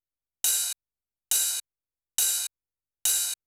909 OP HH -R.wav